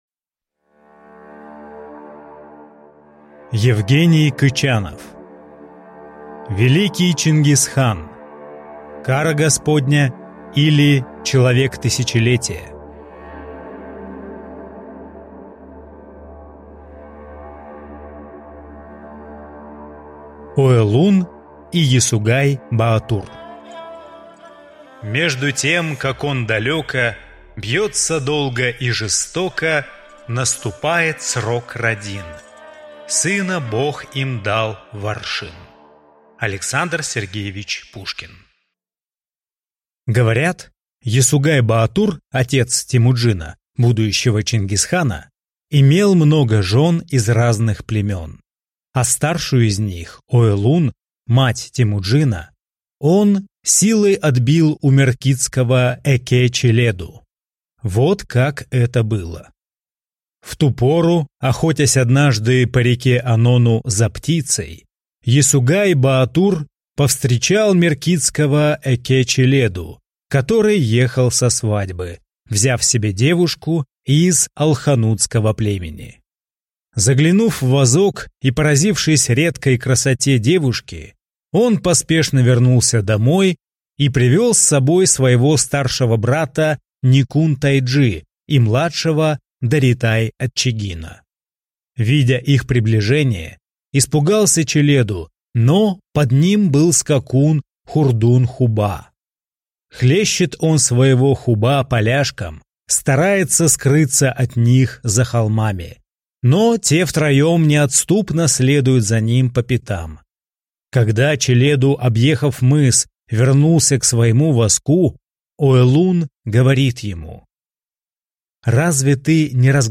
Аудиокнига Великий Чингис-хан. «Кара Господня» или «человек тысячелетия»?